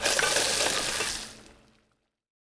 cooking no timer.wav